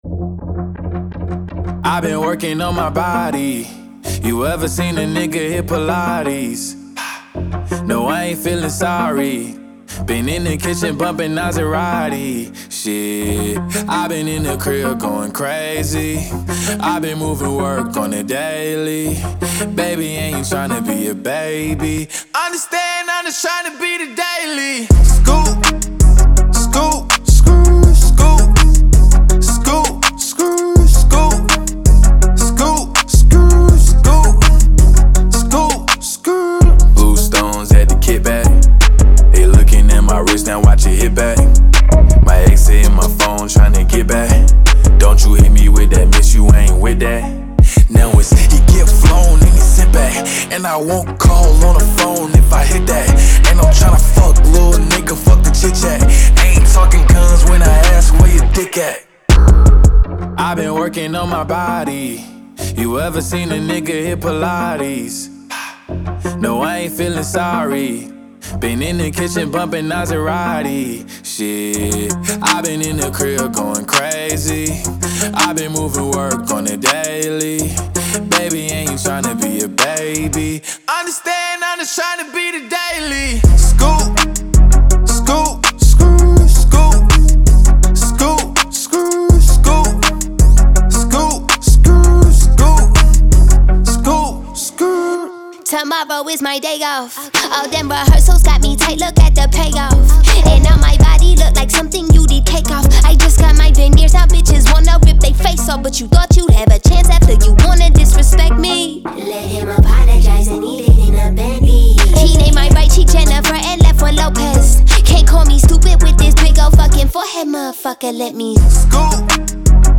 Genre : Hip-Hop